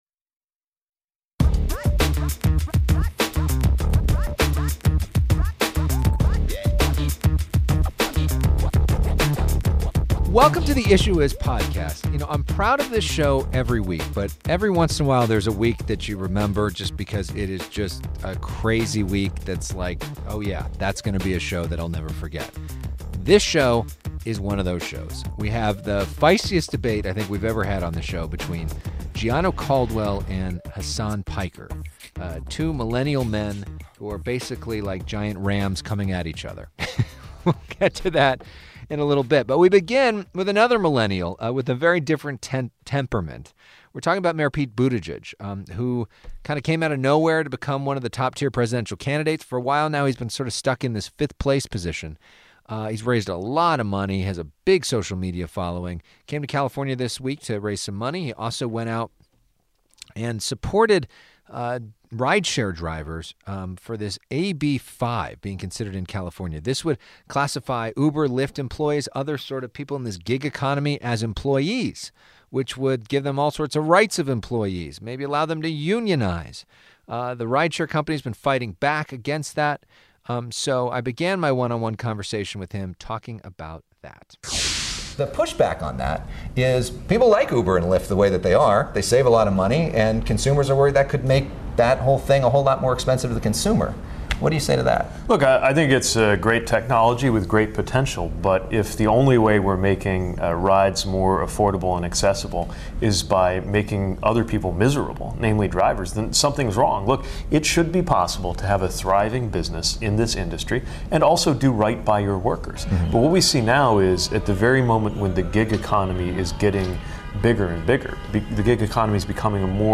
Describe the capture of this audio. This week...the most fiery panel discussion in the history of our show. Plus, a one-on-one sitdown with a top tier presidential candidate.